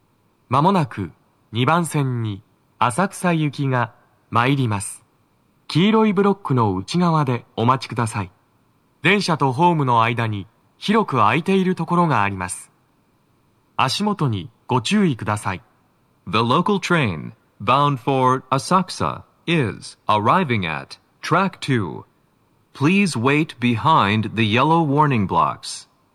スピーカー種類 TOA天井型
鳴動は、やや遅めです。
接近放送1